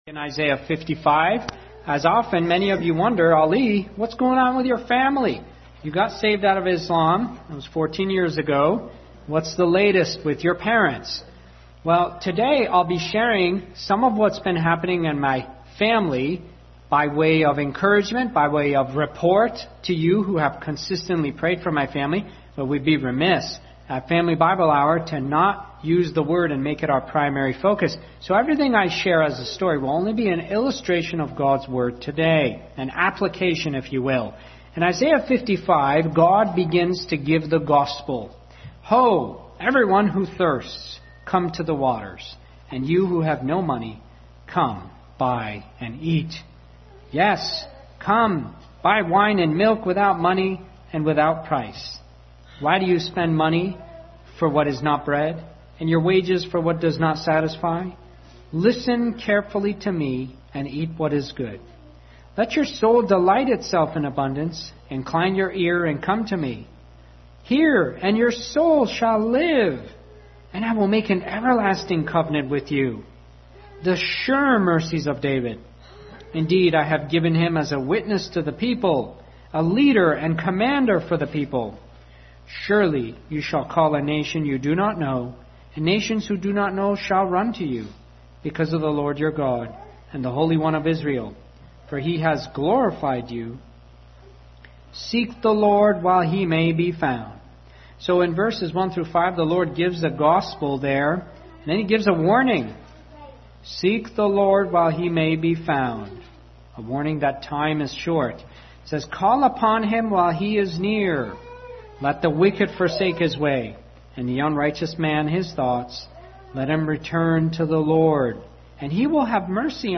Passage: Isaiah 55:1-11 Service Type: Family Bible Hour